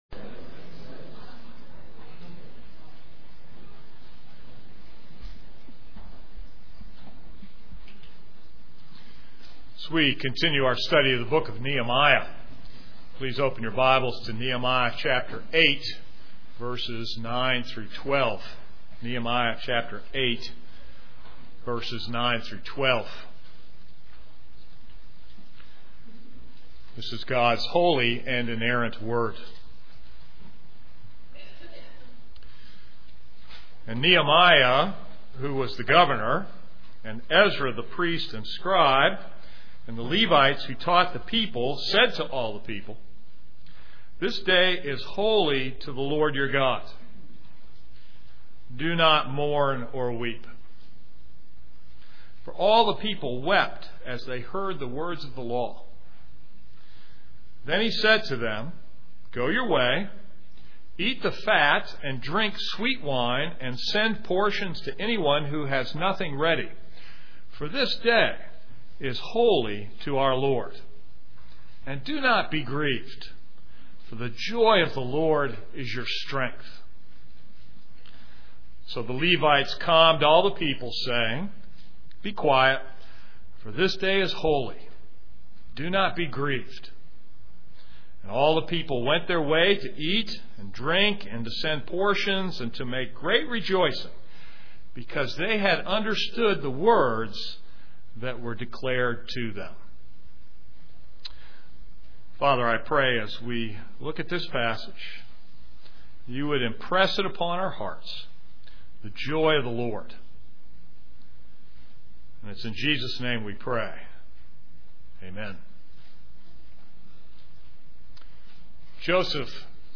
This is a sermon on Nehemiah 8:9-12.